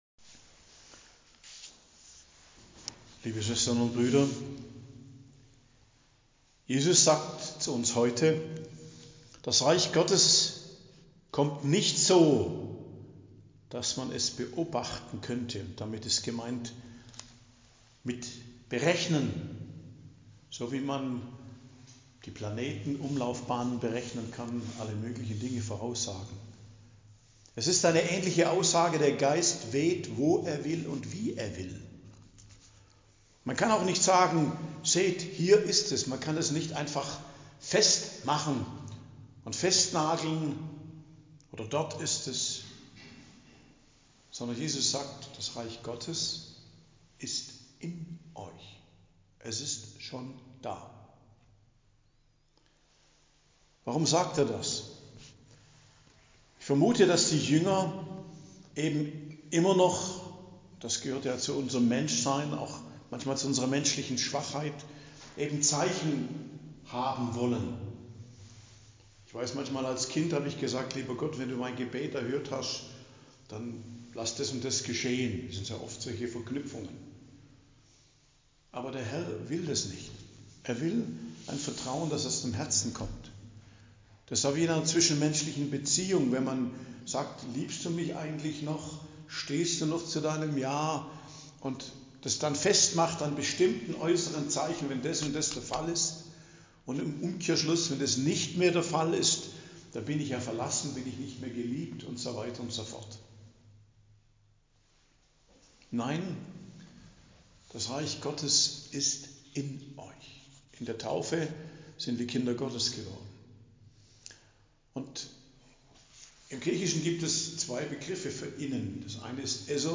Predigt am Donnerstag der 32. Woche i.J. 14.11.2024 ~ Geistliches Zentrum Kloster Heiligkreuztal Podcast